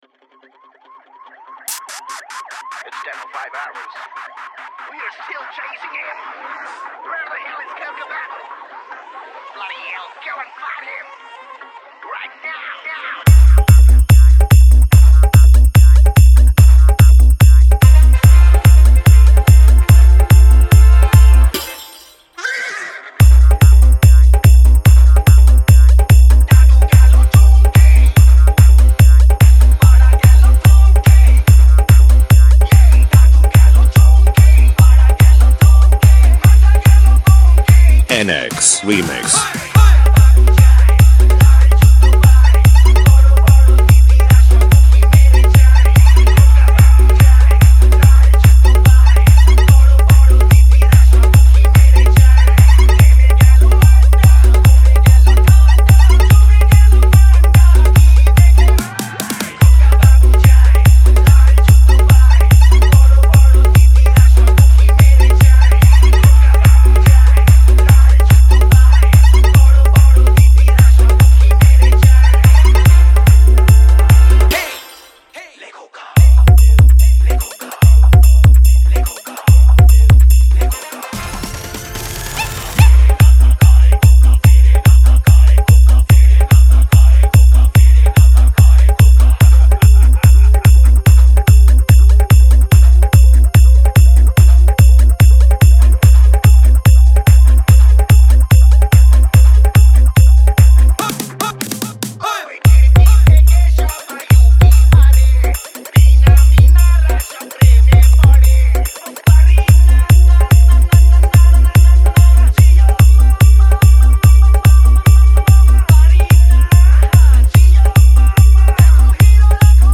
Bengali Dance Humbing Mix